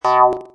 Download Boing sound effect for free.
Boing